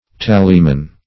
tallyman - definition of tallyman - synonyms, pronunciation, spelling from Free Dictionary
Tallyman \Tal"ly*man\, n.; pl. Tallymen.